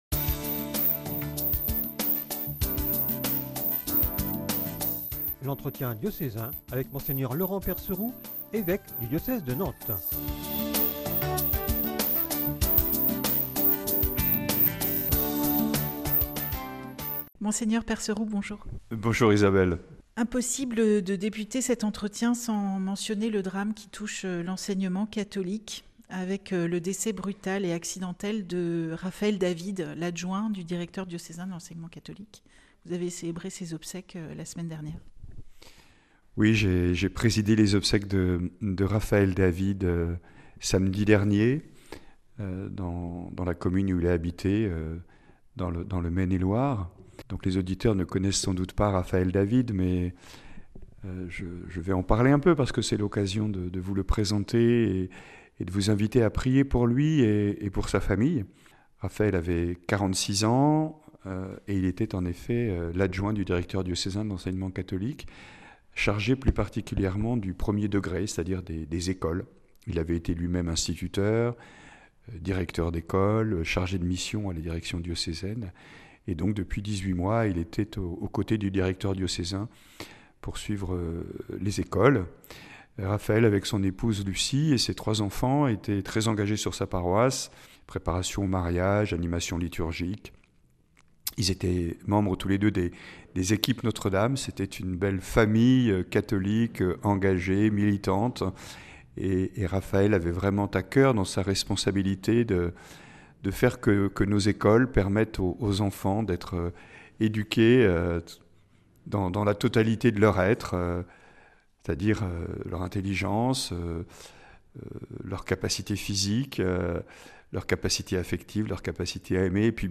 Entretien diocésain avec Mgr Percerou